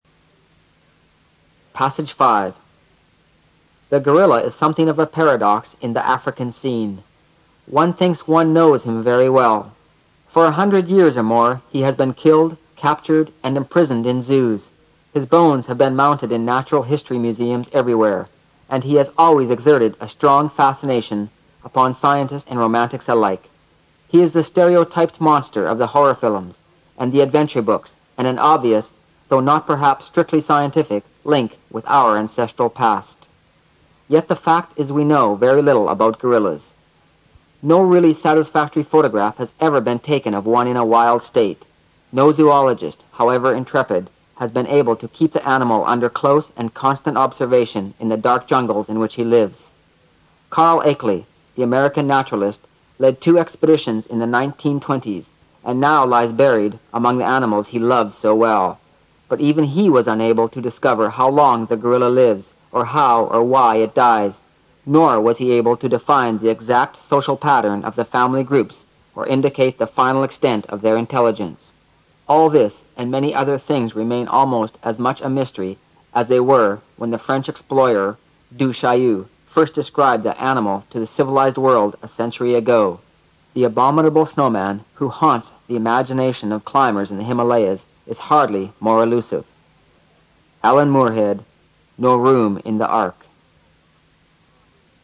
新概念英语85年上外美音版第四册 第5课 听力文件下载—在线英语听力室